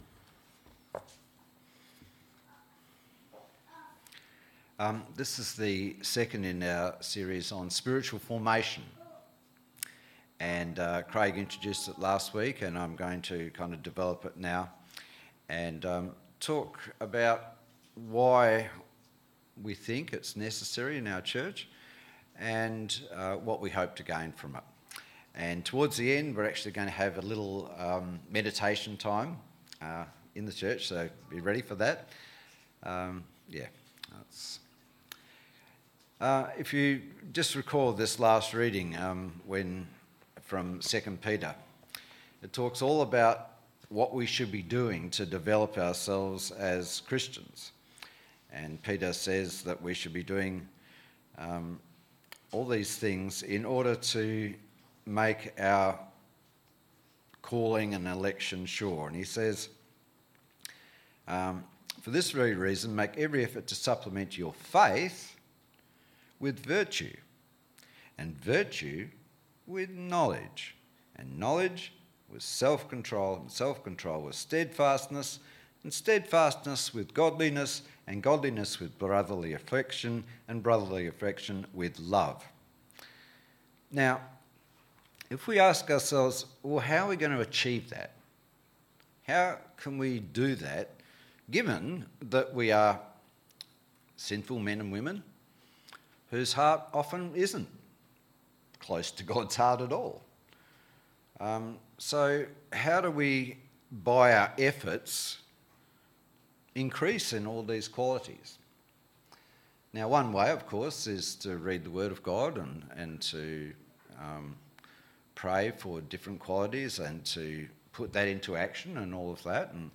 Service Type: Sunday AM Topics: contemplation , growth , Spiritual Formation « Spiritual Formation Through Contemplation